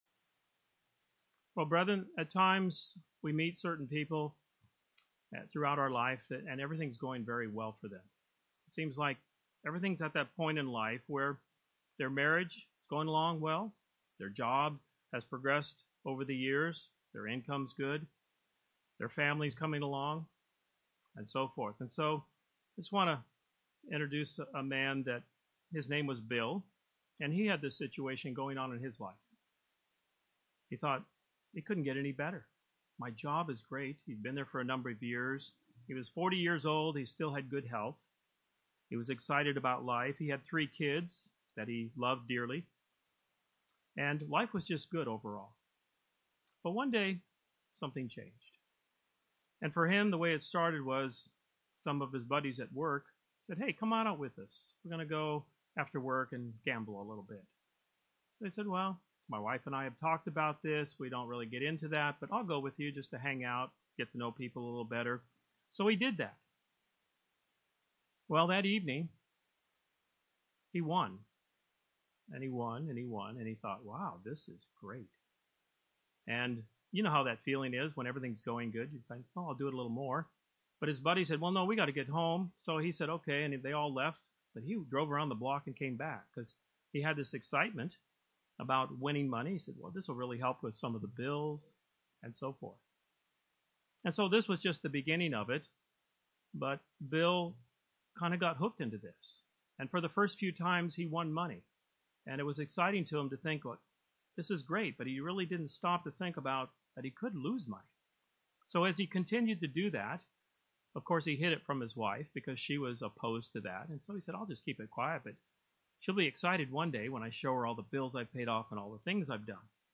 The offer of salvation is a supreme gift, but God does require that we let go of our old ways. This sermon covers two necessary steps for yielding to God.